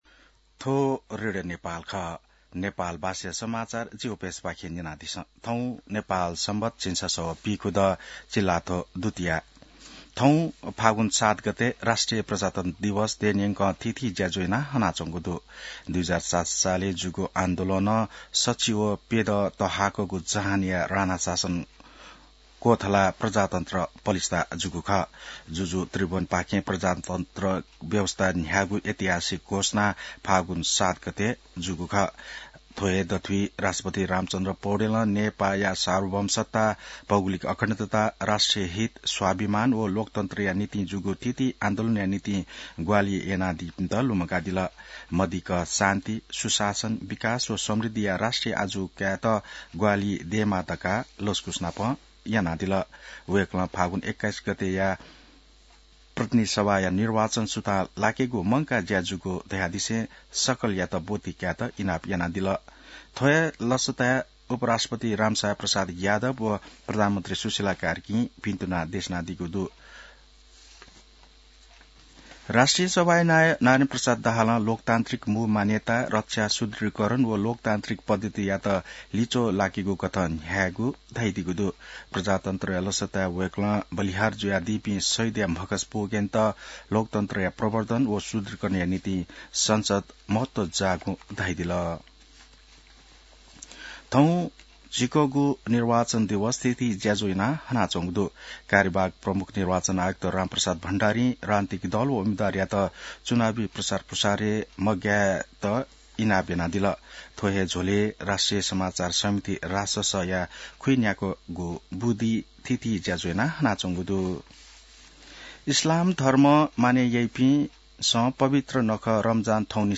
An online outlet of Nepal's national radio broadcaster
नेपाल भाषामा समाचार : ७ फागुन , २०८२